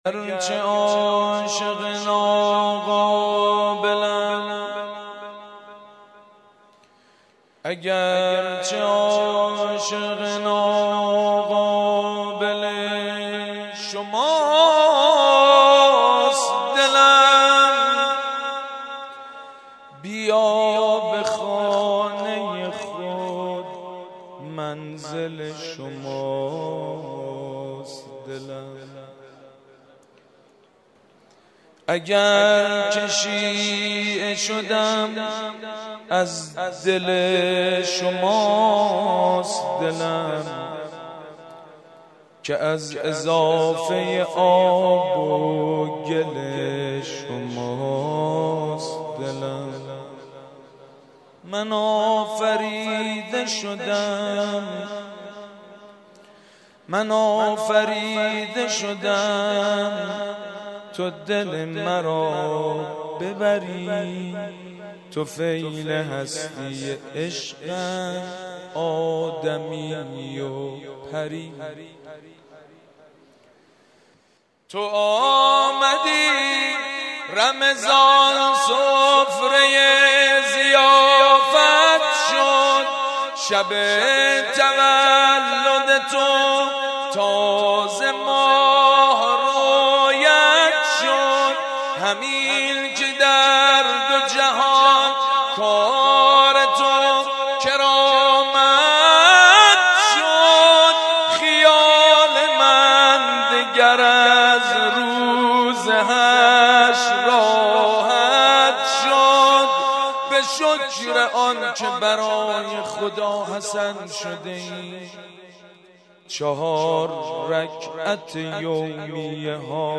04.madh khani.mp3